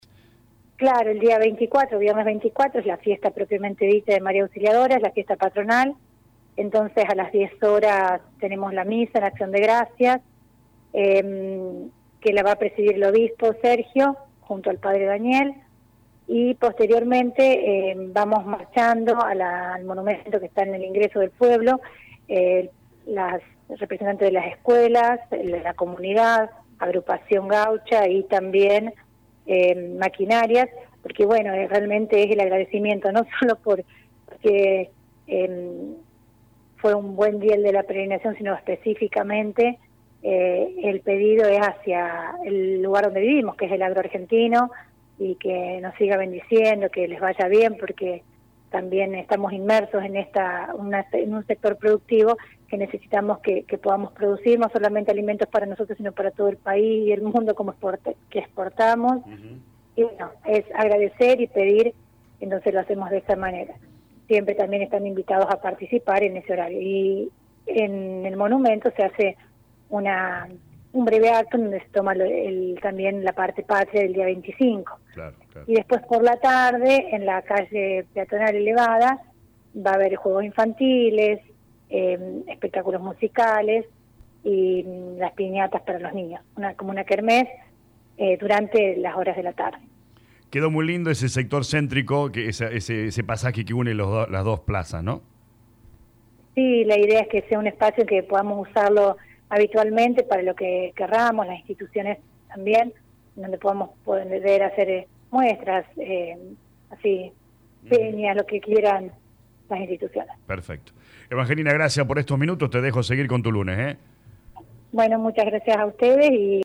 Así lo confirmó la intendente de Colonia Vignaud Lic. Evangelina Vigna en diálogo con LA RADIO 102.9 FM y afirmó que fue «un fin de semana muy lindo, muy concurrido y con un clima que ayudó a que todo se desarrolle de la mejor manera».